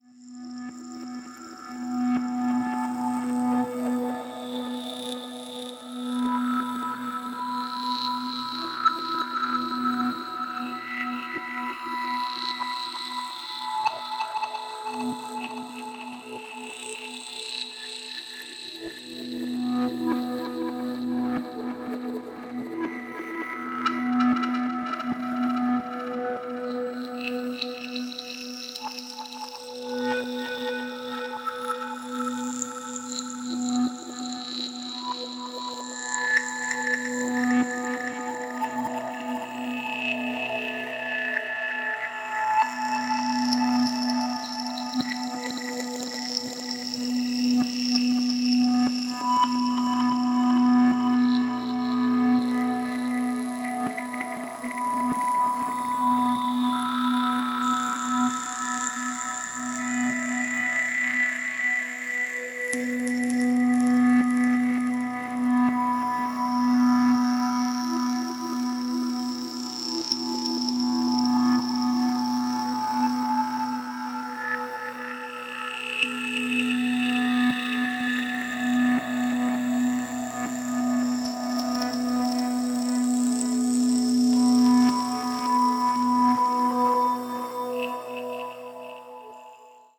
フロア完全対応なディープ・テクノ推薦盤。